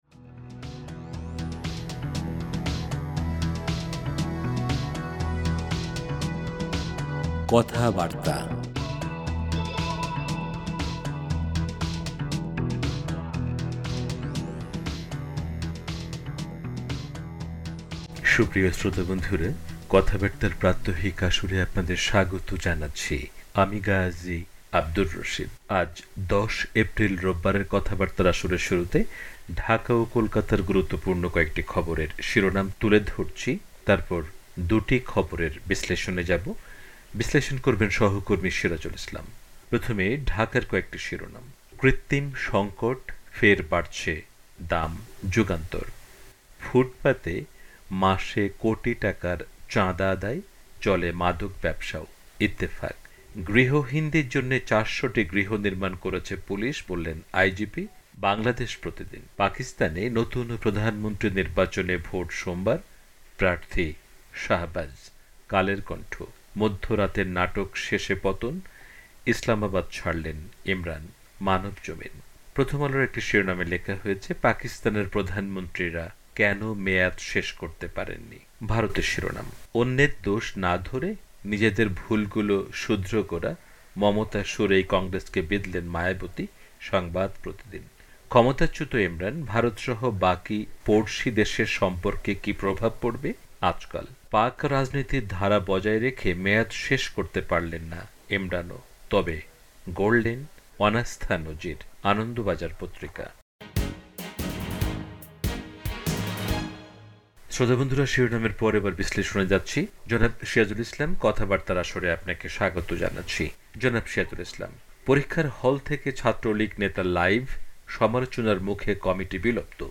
কথাবার্তা